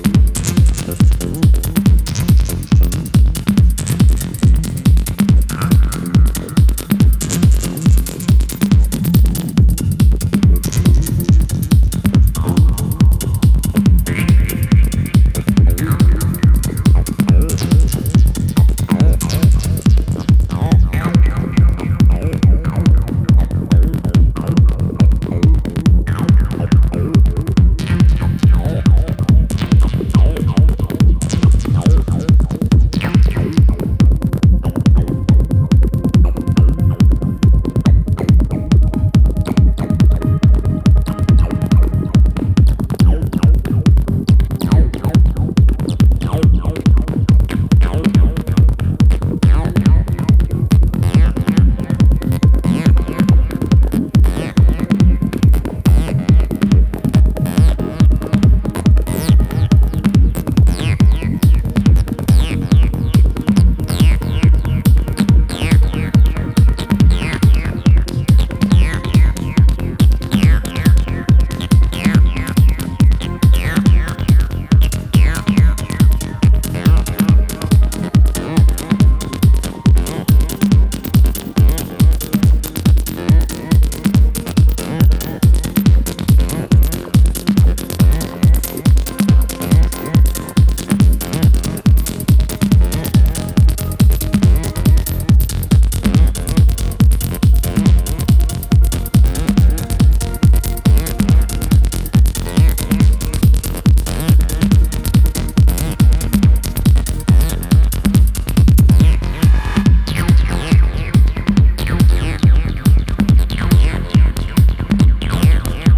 140BPMのパンピンなピークタイムチューン